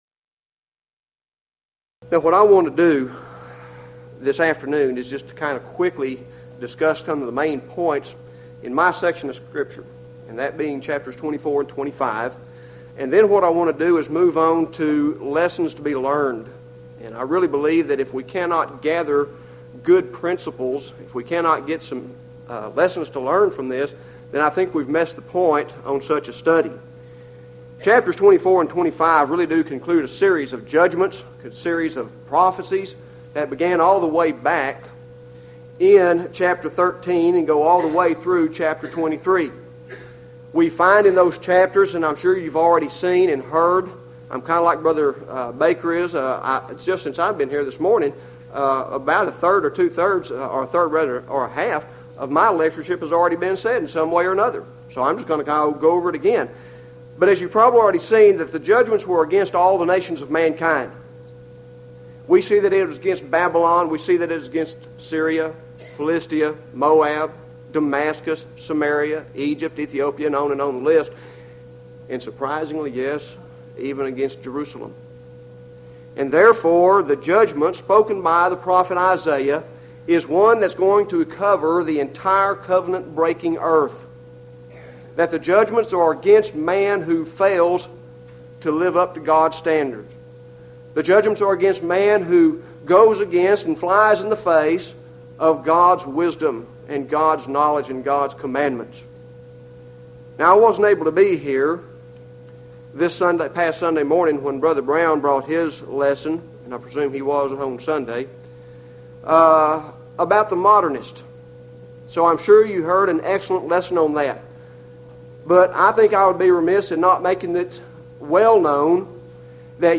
Houston College of the Bible Lectures